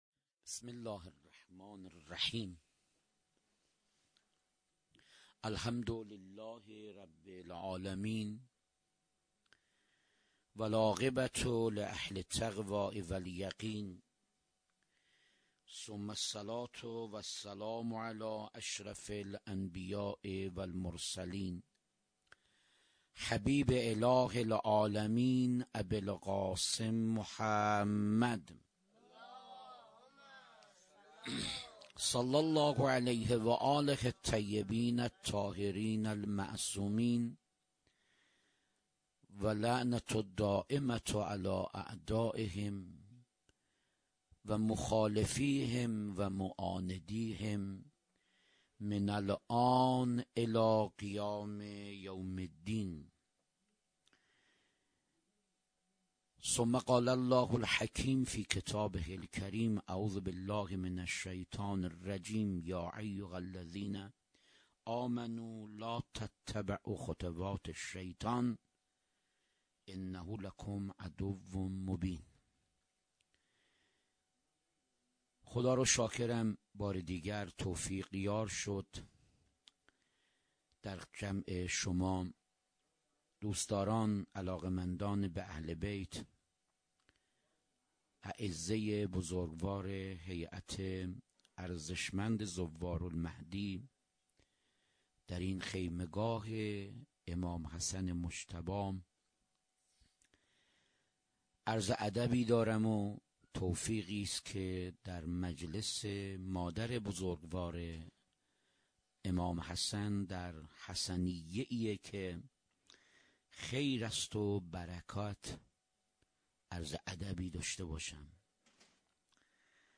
هیئت زواراباالمهدی(ع) بابلسر - سخنرانی